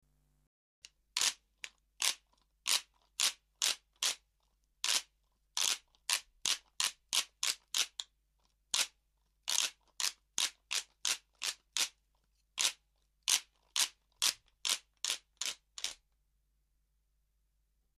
棒を持って振ると　板どうしがあたって音がでます
打楽器(20)